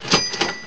cash register